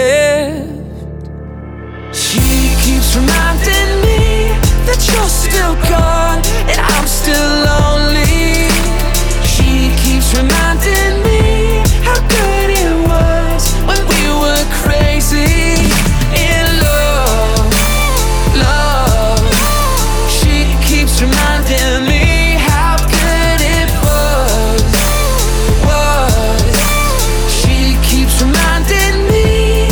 • Pop
pop ballad